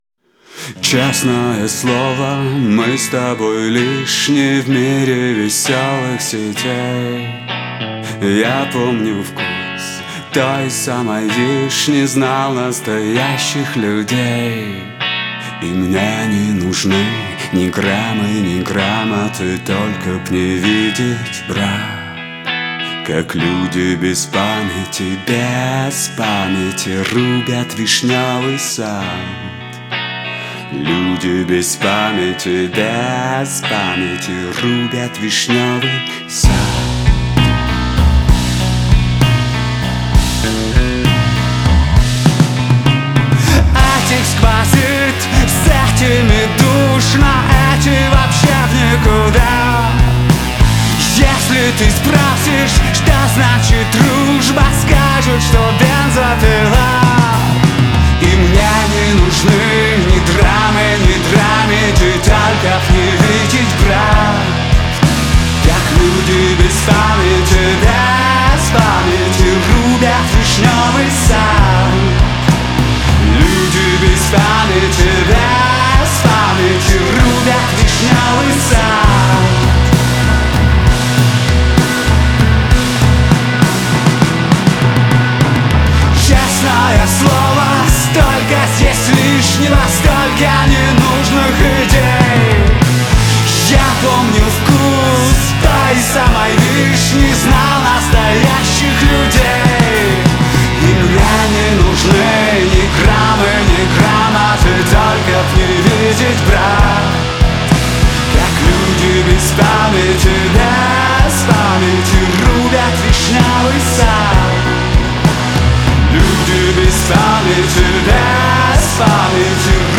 Хаус музыка